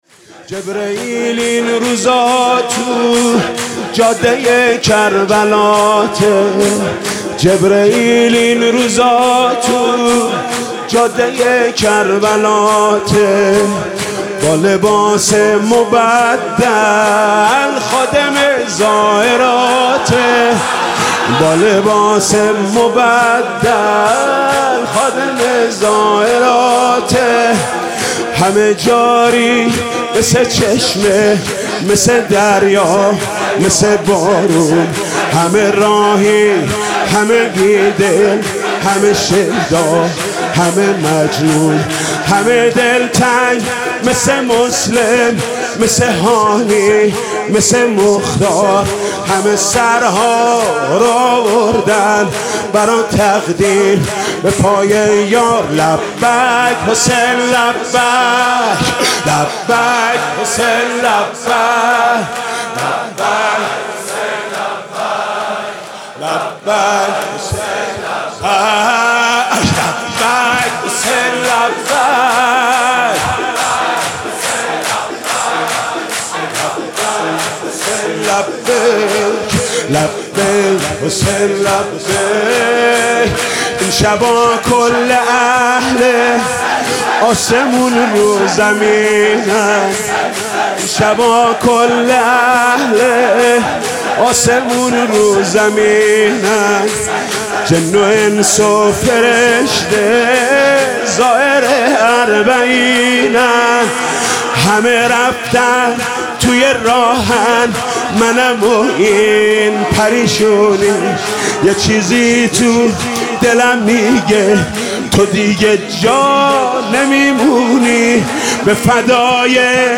متن مداحی